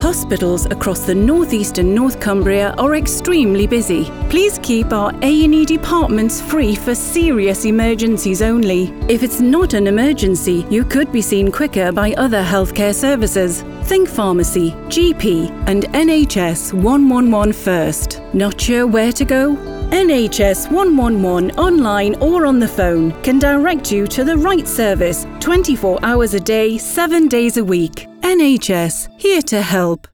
A&E is busy radio advert